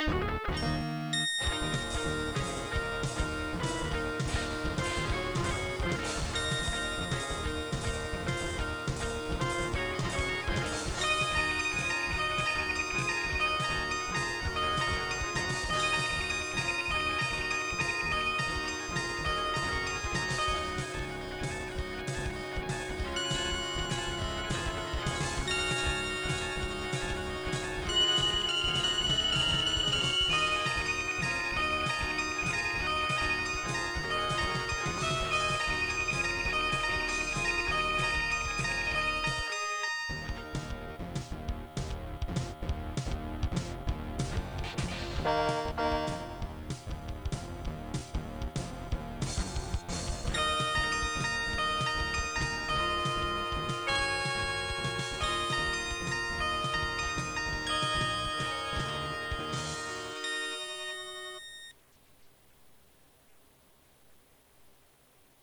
Piece of lunatic music.